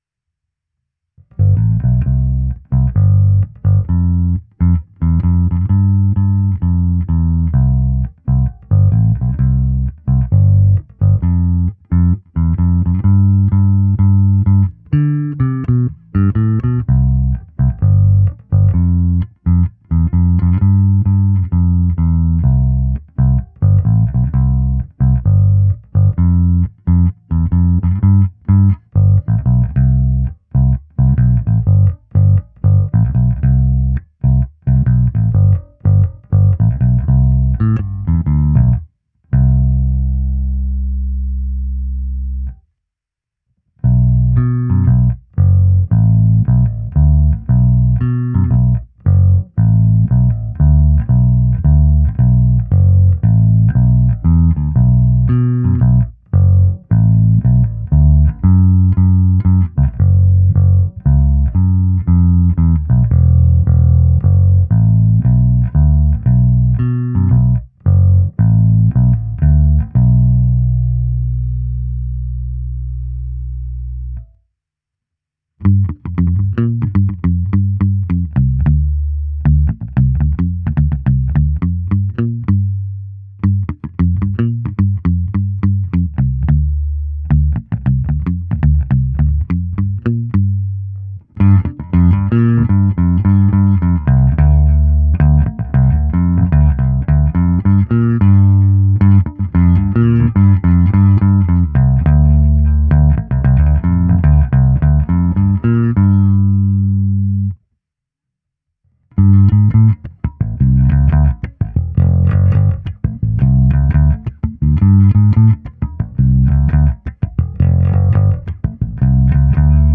Ukázka s plně otevřenou tónovou clonou
Ukázka s tónovou clonou na polovinu
Nahrávka se simulací aparátu, kde bylo použito i zkreslení a hra slapem, která je sice díky překážejícímu snímači u krku nesnadná, ale ne nemožná.